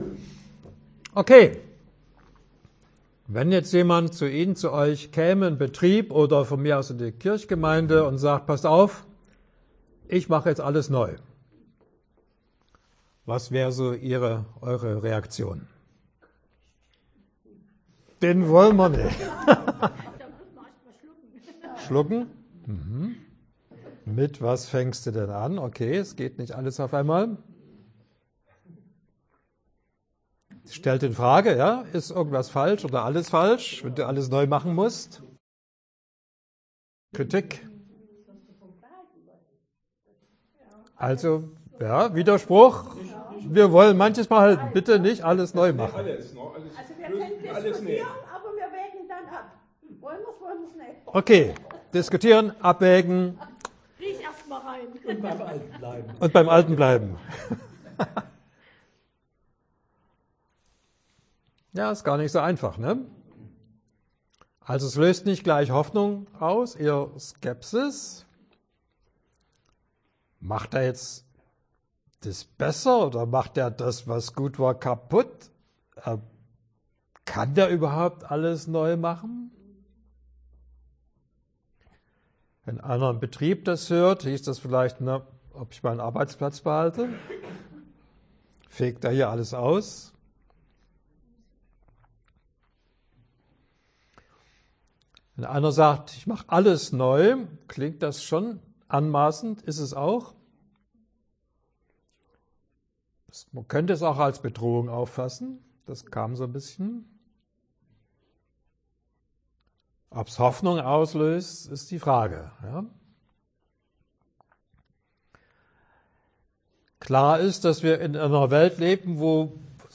Unter dem ausführlichen Manuskriptsind auch die 4 ausführlichen mündlichen Vorträge verlinkt!